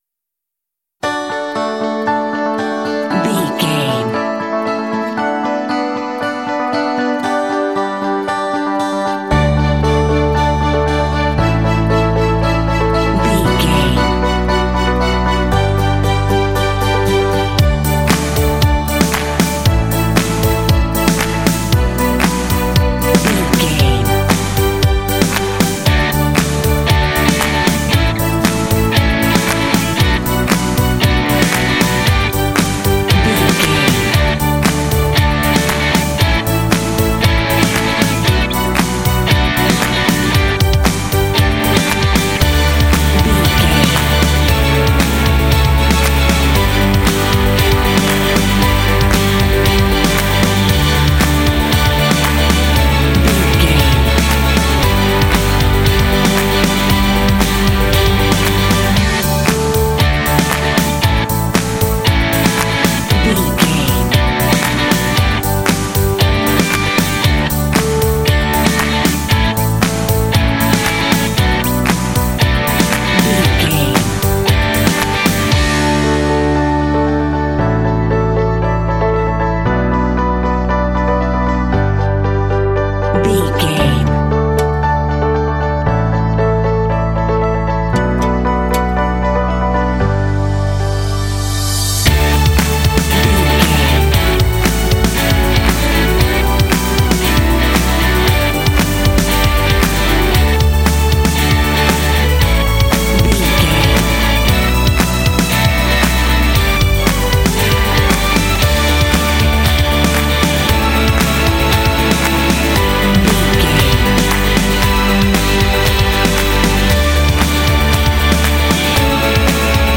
Uplifting
Ionian/Major
D
optimistic
happy
piano
electric guitar
acoustic guitar
strings
bass guitar
drums
rock
contemporary underscore
indie